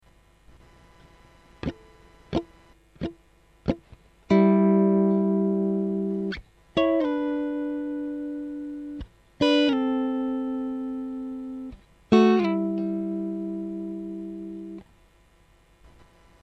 Another great 6th based lick you can play in the blues are licks based on sliding between the notes of the 6th chord and the 9th chord.
Blues 6 to 9 6th Licks